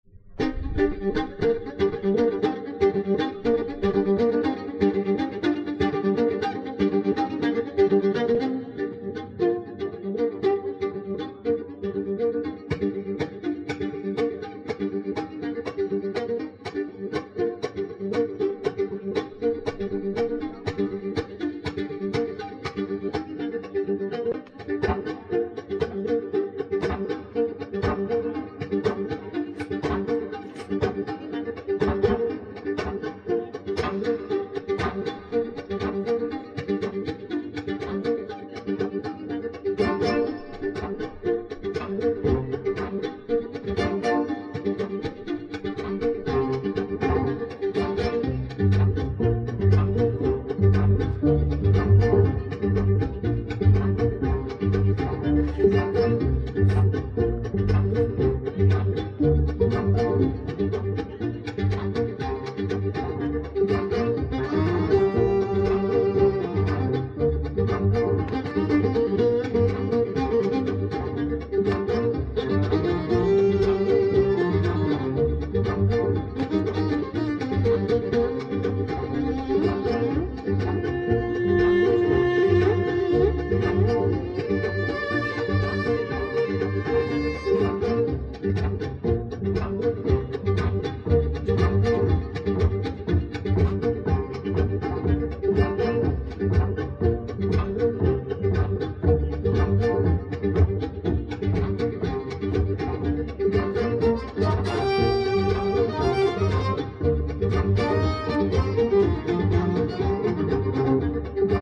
Funk Jazz / Loop Layer Effects